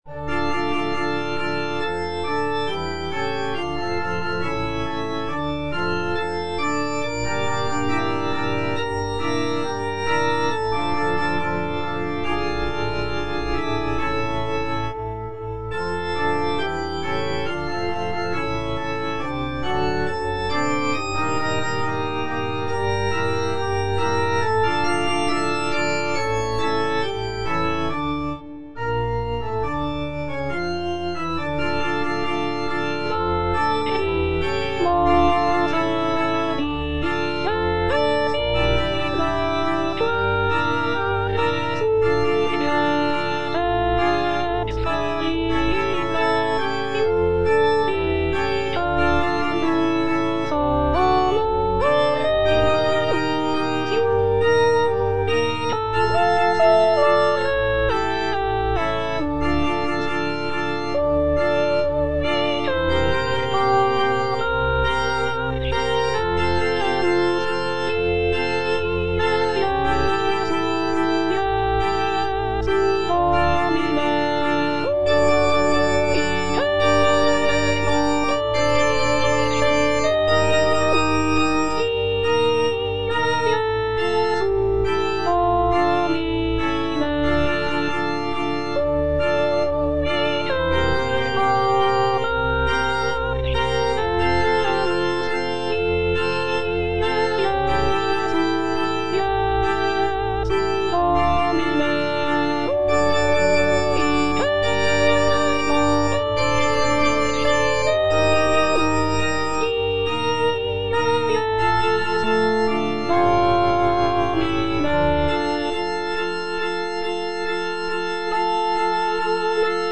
F. VON SUPPÈ - MISSA PRO DEFUNCTIS/REQUIEM Lacrimosa - Bass (Emphasised voice and other voices) Ads stop: auto-stop